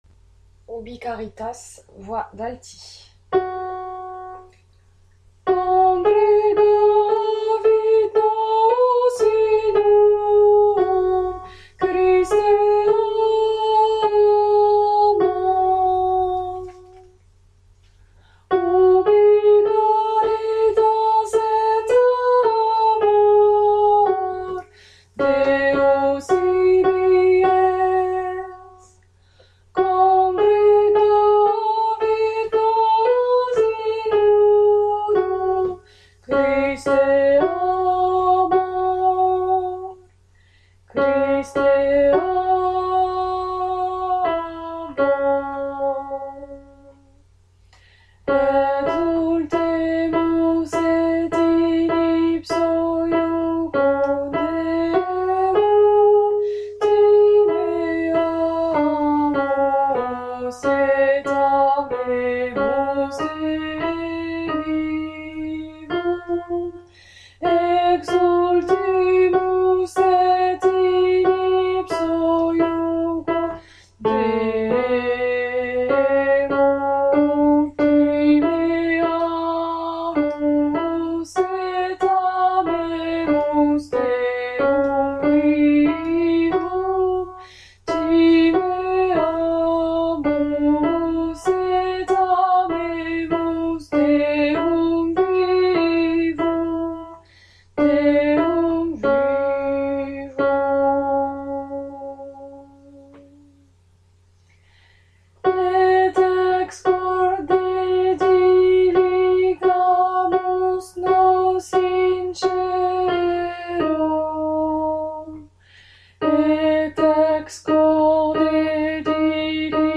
Altos
ubi-caritas-alto.mp3